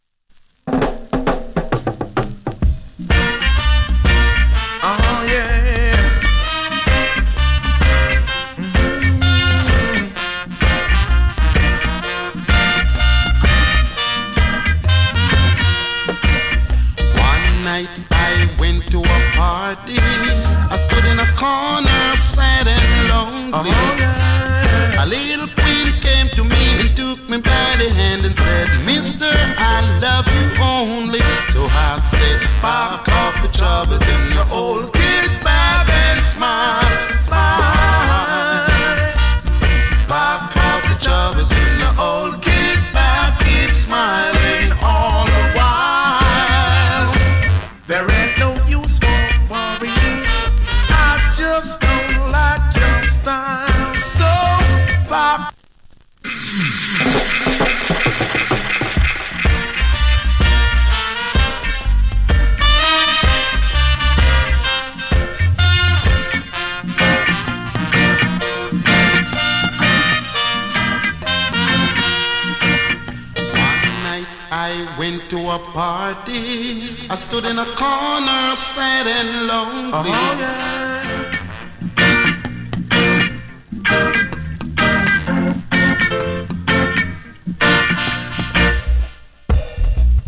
SKA OLD HITS